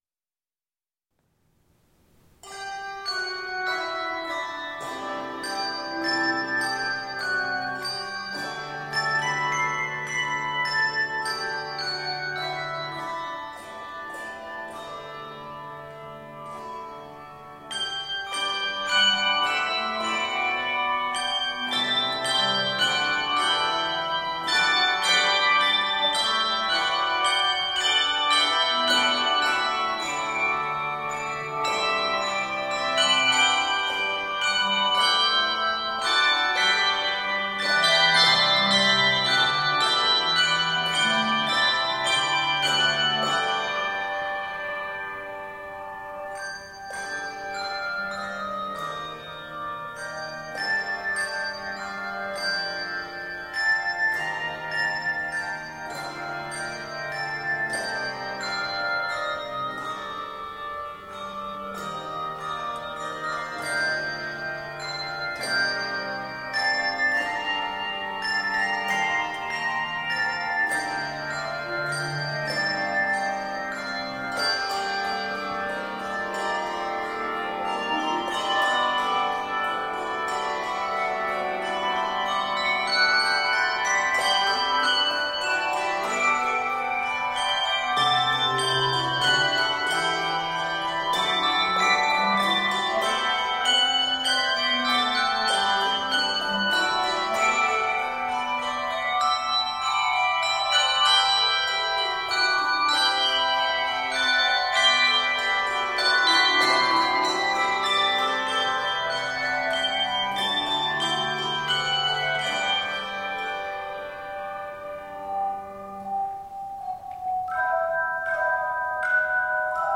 It is 110 measures and is scored in G Major and g minor.